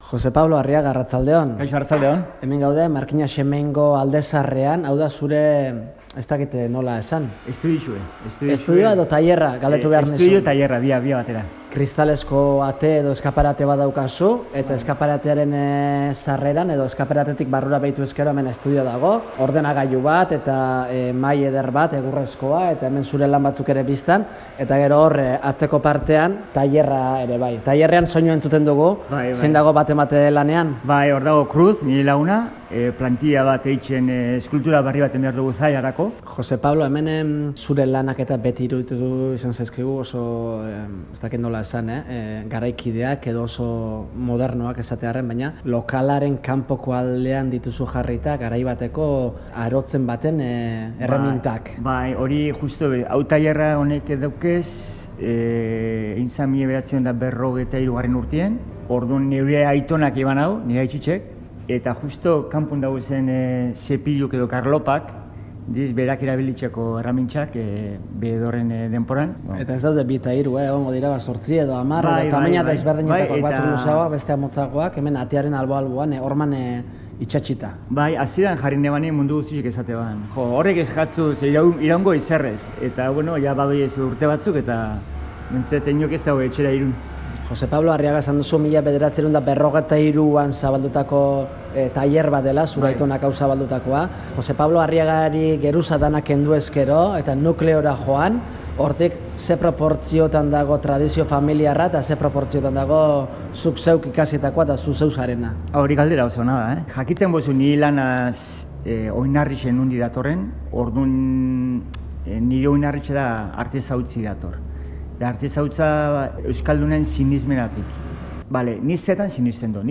Markina-Xemeingo alde zaharrean izan gara